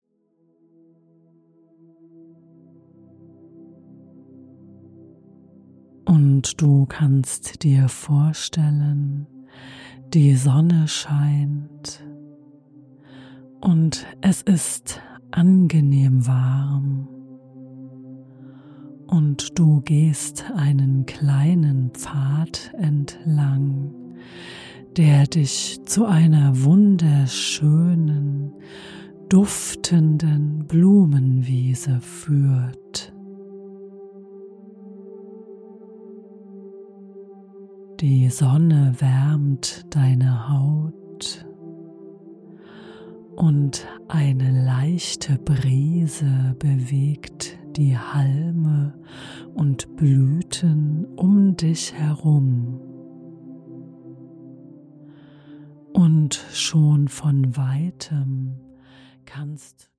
Fantasiereise Blumenwiese - Via Fantasia - geführte Meditationen
Fantasiereise Blumenwiese als mp3-Download
In dieser geführten Fantasiereise wirst du dazu eingeladen, in Gedanken eine Blumenwiese zu besuchen.